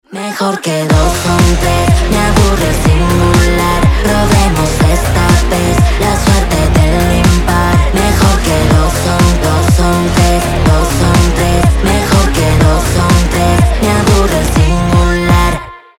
• Качество: 320, Stereo
Pop Rock